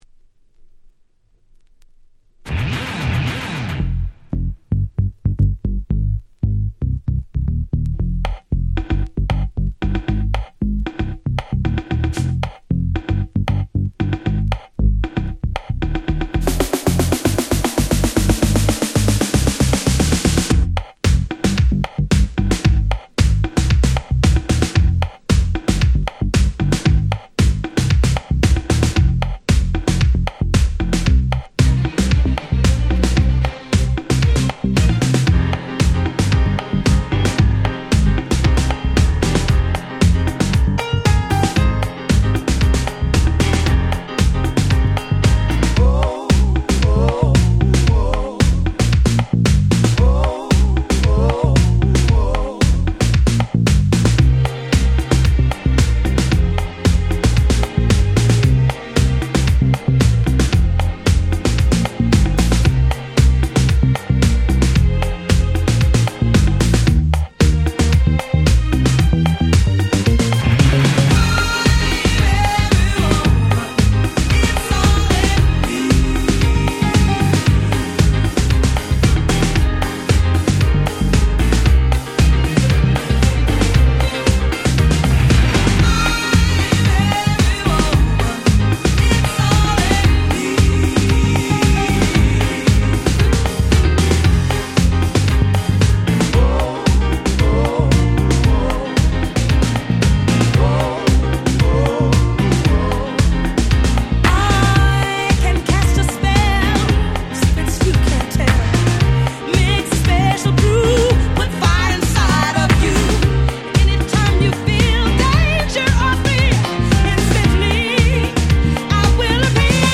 Disco
Dance Classics